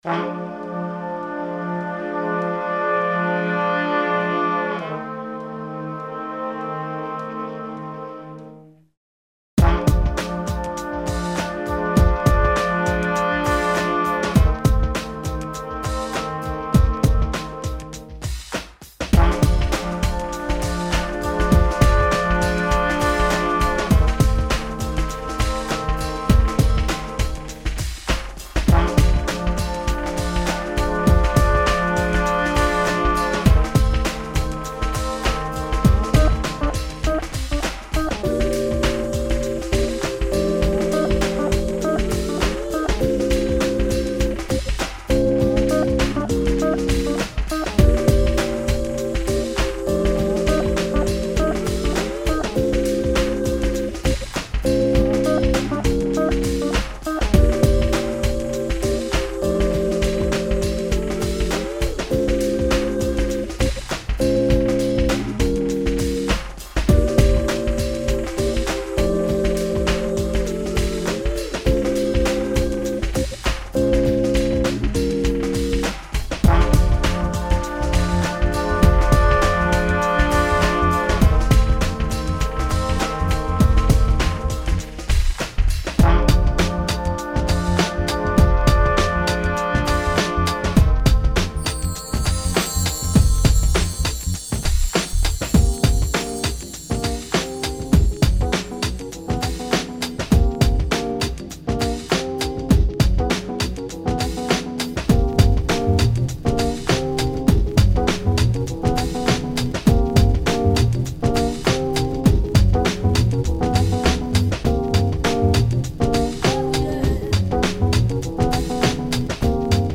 an acid jazz remix